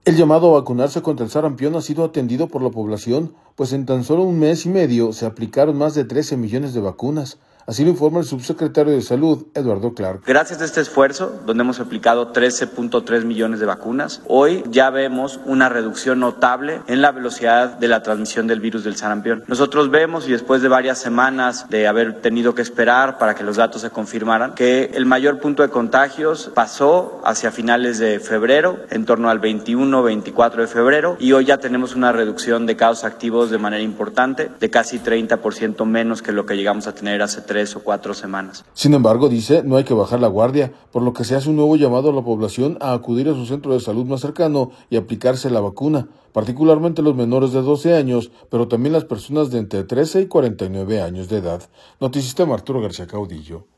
El llamado a vacunarse contra el sarampión ha sido atendido por la población, pues en tan sólo un mes y medio se aplicaron más de 13 millones de vacunas, así lo informa el subsecretario de Salud, Eduardo Clark.